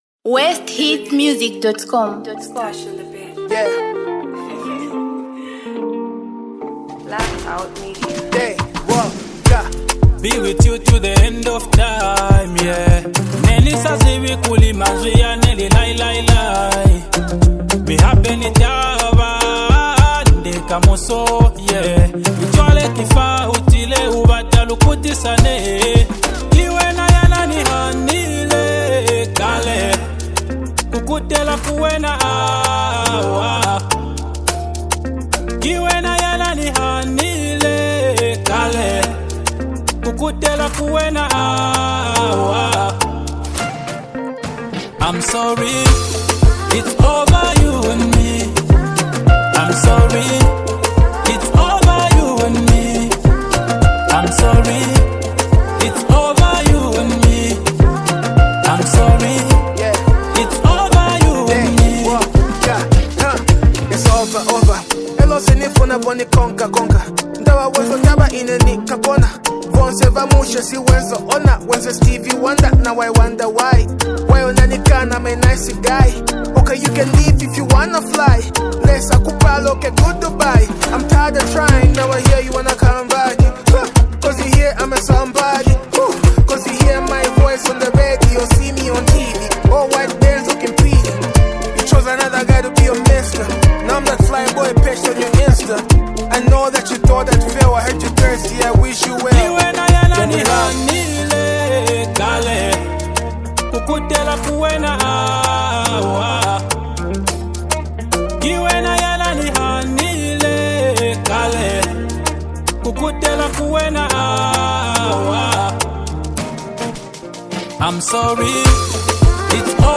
Category: Mbunga Music